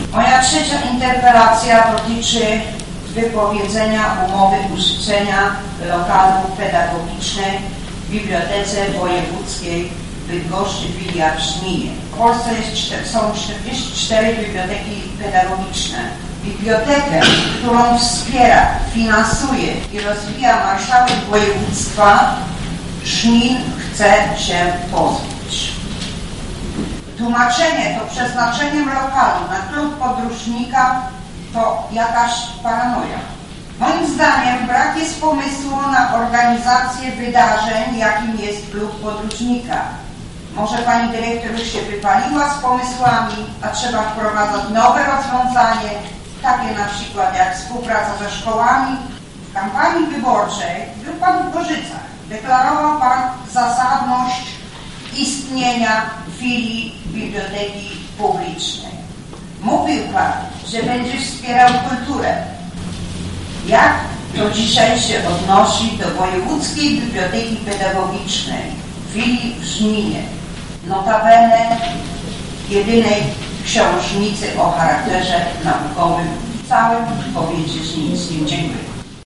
Na XII sesji Rady Miasta w Żninie, po przyjętym przez radnych sprawozdaniu międzysesyjnym burmistrza, kolejnym punktem spotkania była możliwość kierowania przez rajców zapytań i interpelacji w stronę Roberta Luchowskiego.